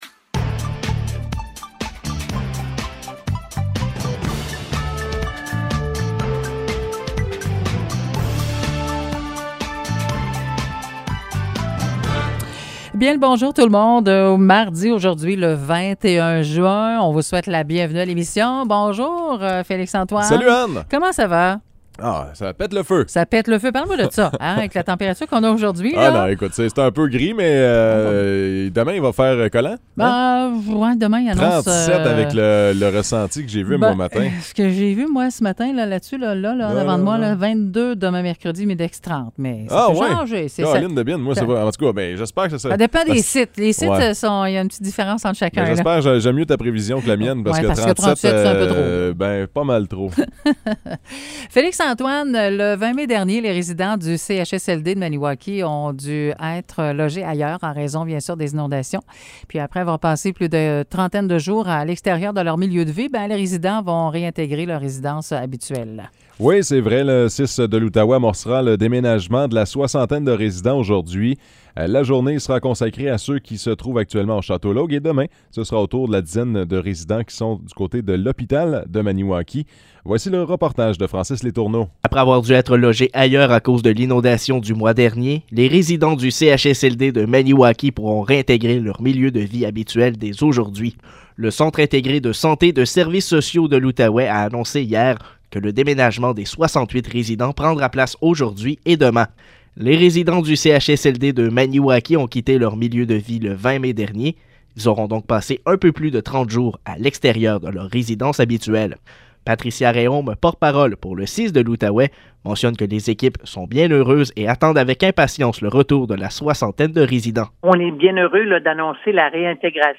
Nouvelles locales - 21 juin 2022 - 9 h